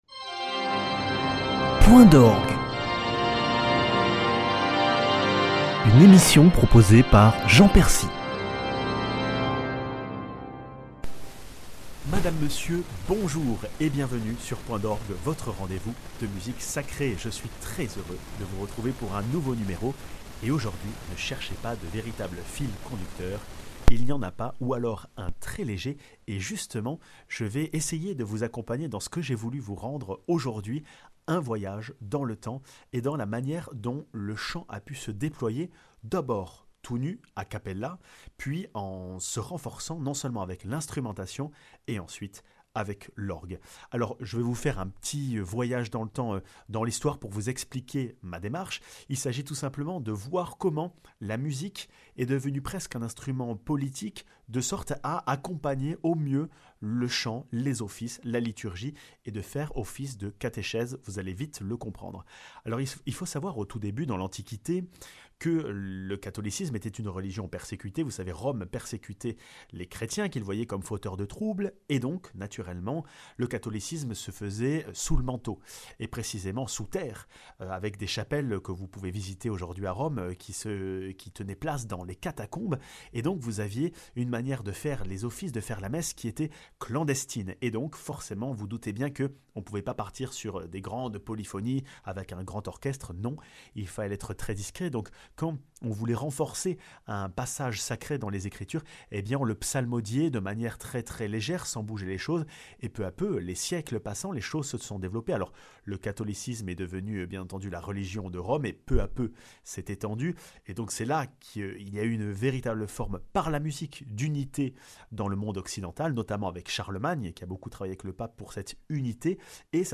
Au fil des siècles, le chant choral polyphonique s'est enrichi de nombreux instruments : l'orgue et l'orchestre (Polyphonie corse, Sacta Maria de Mozart et Sanctus d'Escaich)